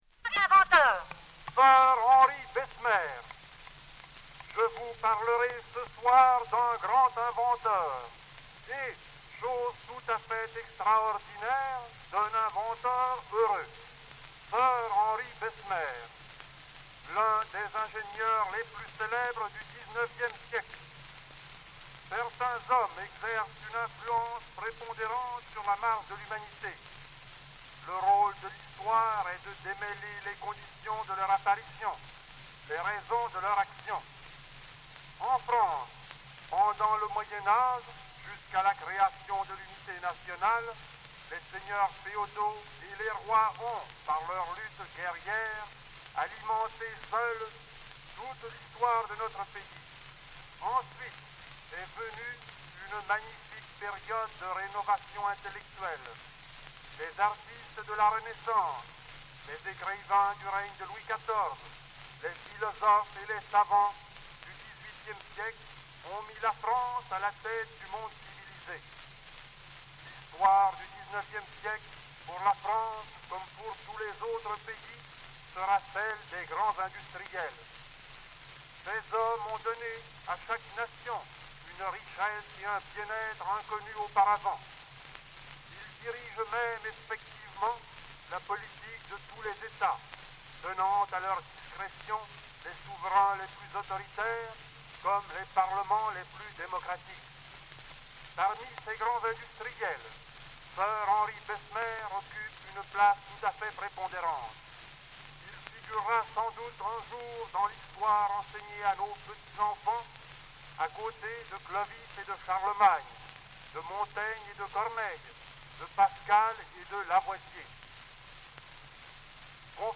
Les cylindres phonographiques du lycée Lakanal, enregistrés en amateur
Il s'agit vraisemblablement d'un professeur qui lit une coupure de journal.
Deuxième prise de sons, sur un autre cylindre.
Le son aigu caractéristique du début montre que la personne a commencé à parler avant la pleine rotation du cylindre.